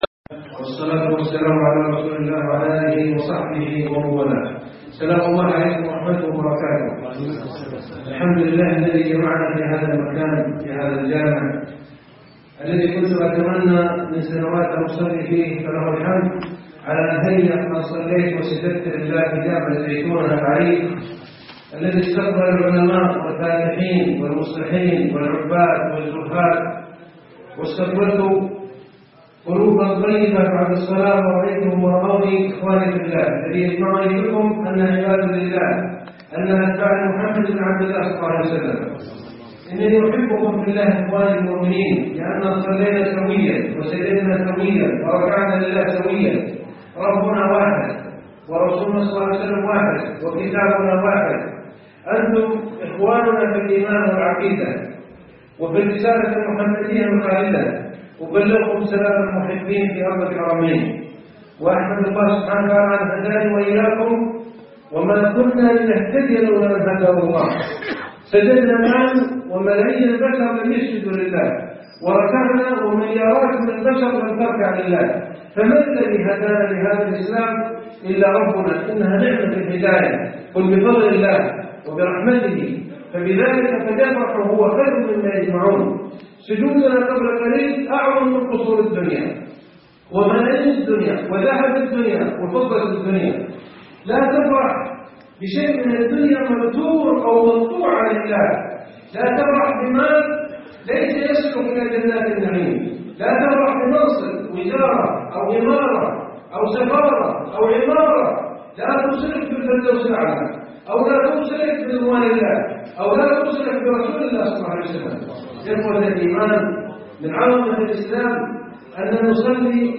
الشيخ عائض القرني بجامع الزيتونة - الدكتور عائض القرنى